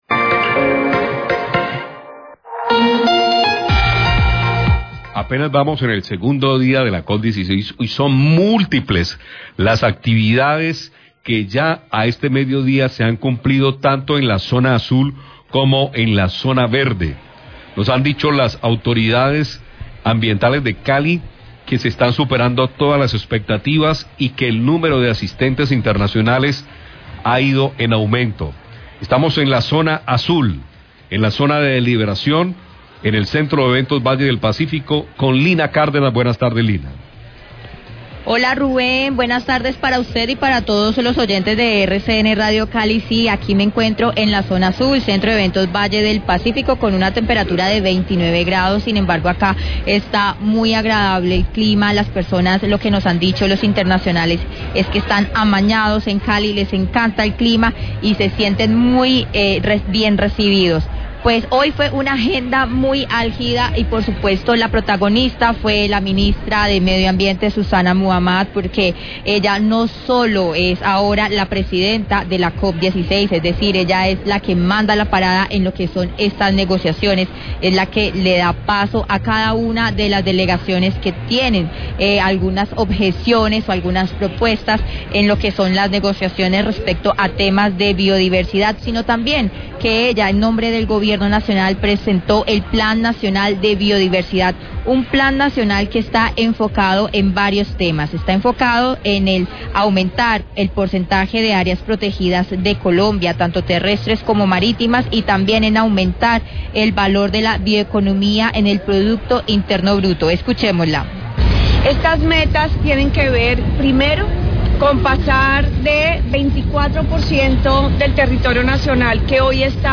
Reportaje desde la Zona Azul de la COP16 en el centro de eventos Valle del Pacífico, donde asumió la presidencia de la COP la ministra de ambiente, Susana Muhamad y presentó el Plan de Acción Nacional de Biodiversidad.